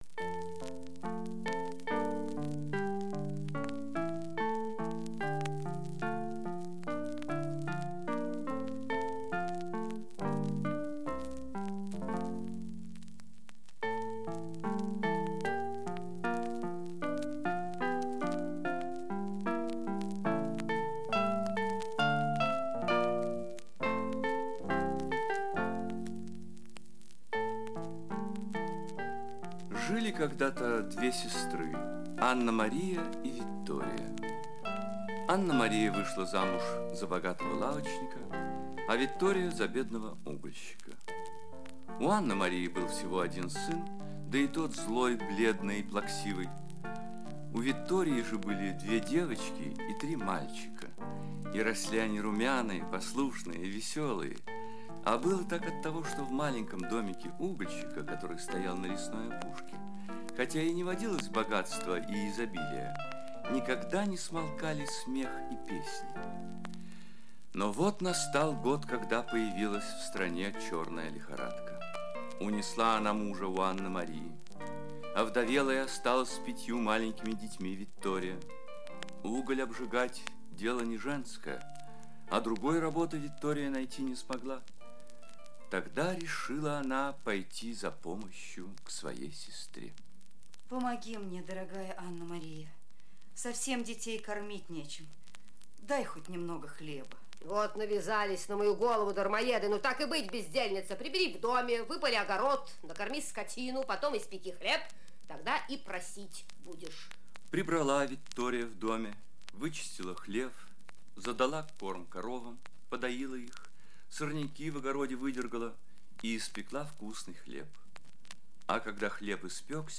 Похлебка из камней - итальянская аудиосказка - слушать онлайн